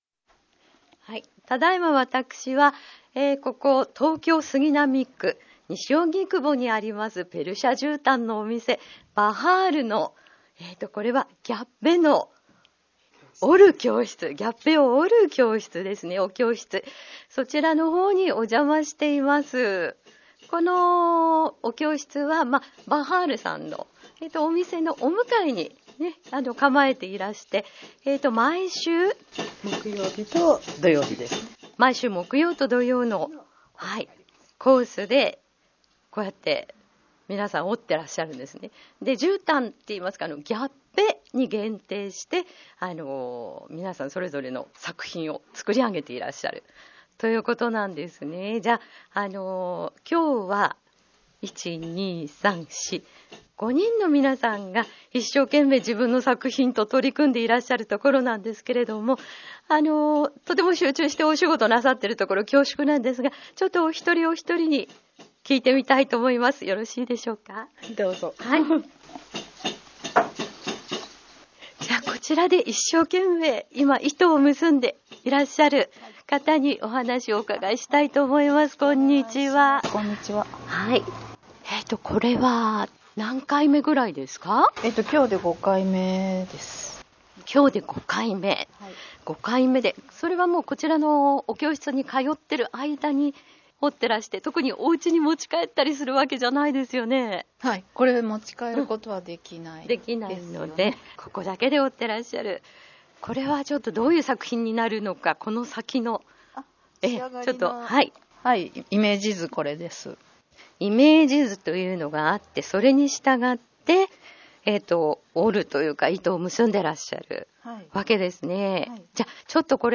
今週はペルシャ絨毯店「バハール」ギャッベ教室の皆さんへのインタビューをお届けします。